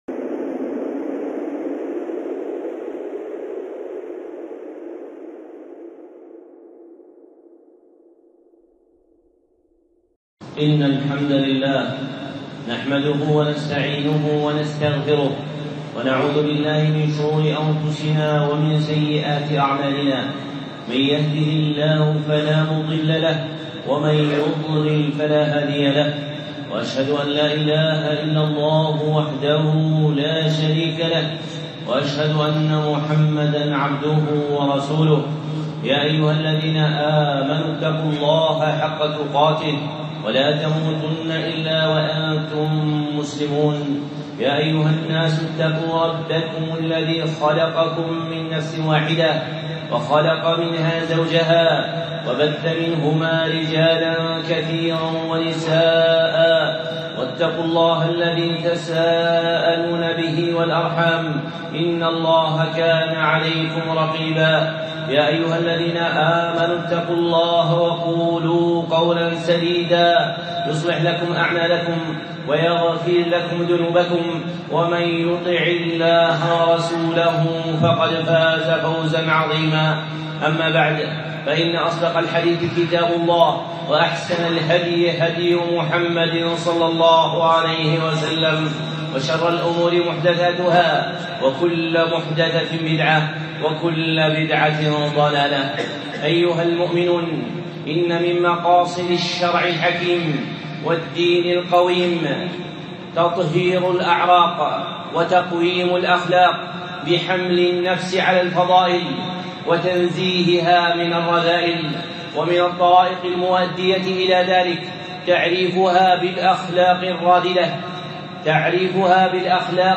خطبة (فضيلة الحزم)